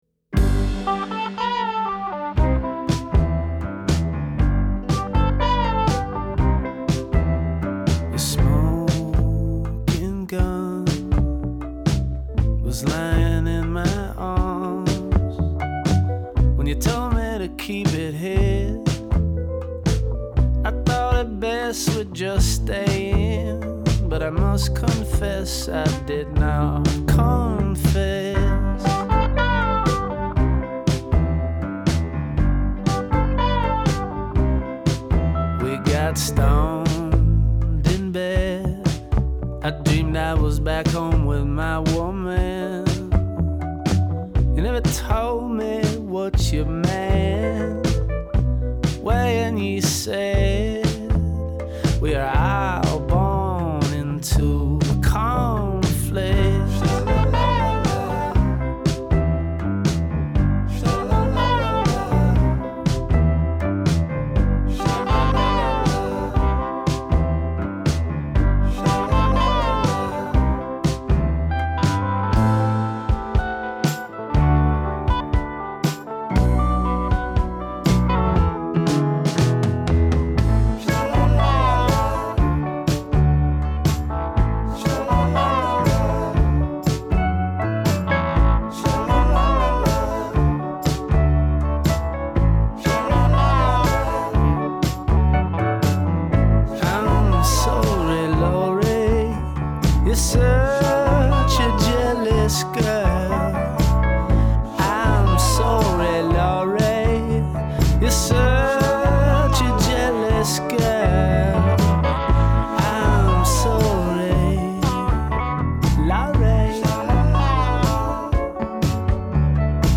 with its perfect melodic build and interesting arrangement.